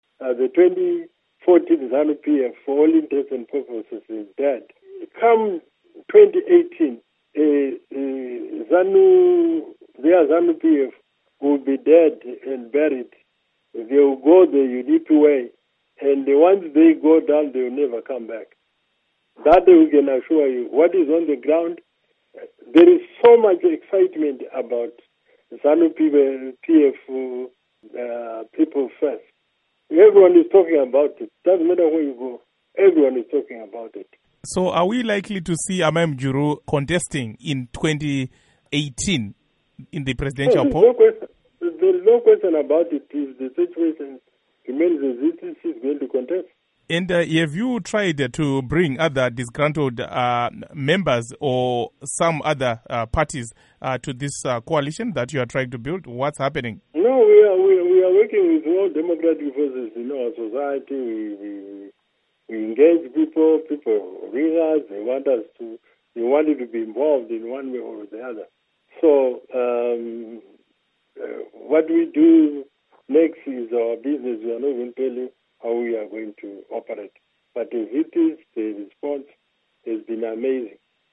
Exclusive Interview With Rugare Gumbo